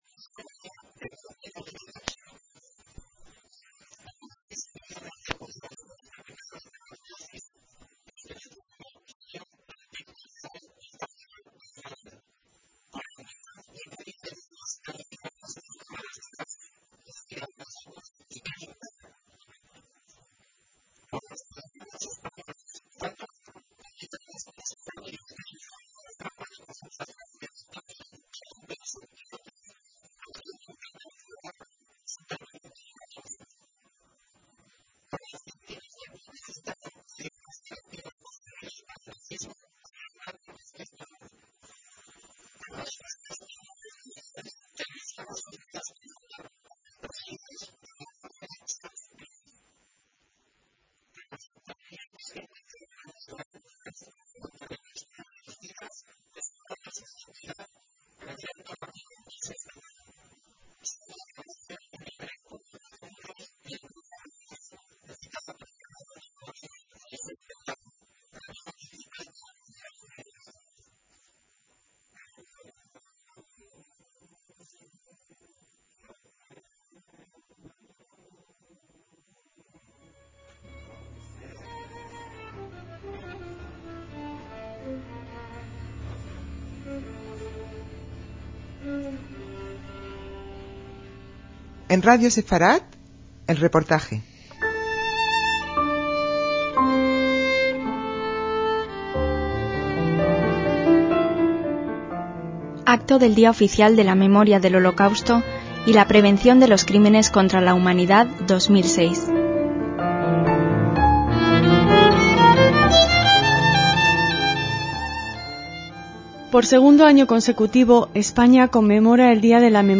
Reportaje sobre el Acto Oficial de la Memoria de la Shoa en el Congreso de los Diputados
DECÍAMOS AYER (27/1/2006) - Un nuevo reportaje del acto oficial de la Memoria de la Shoá en el Congreso de los Diputados de Madrid en 2006.